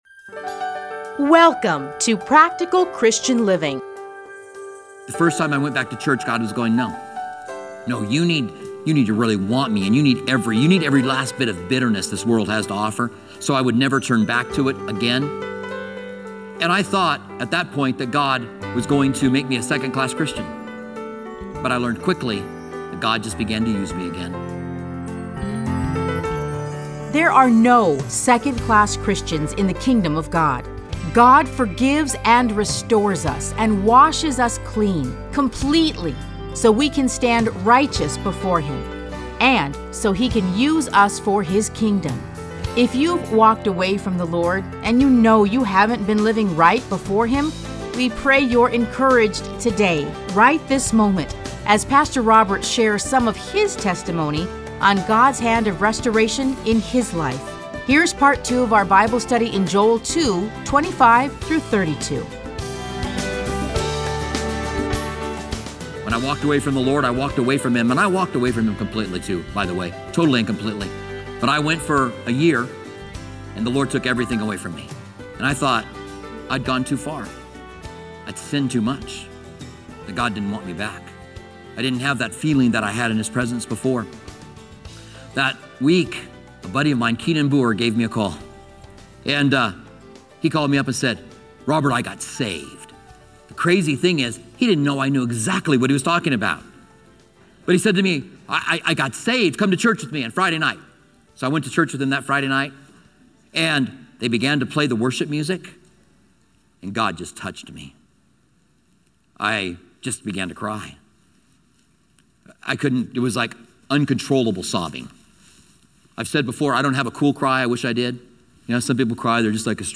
Listen to a teaching from Joel 2:25-32.